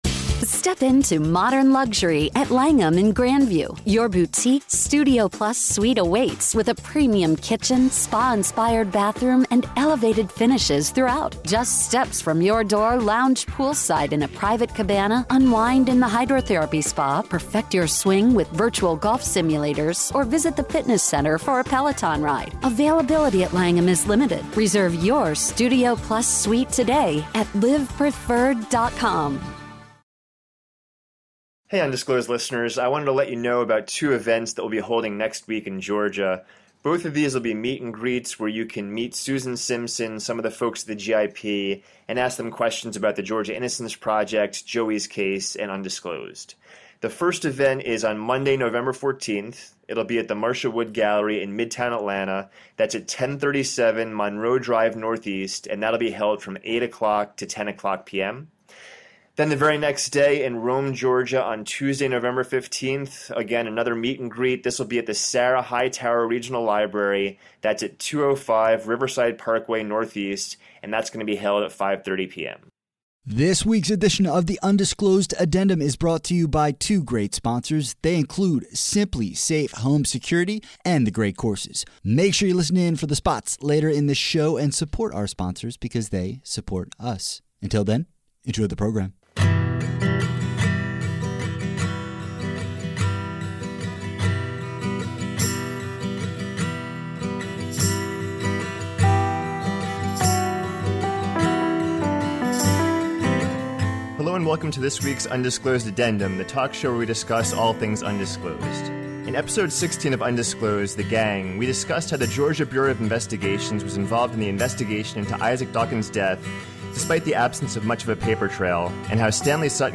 Episode scoring music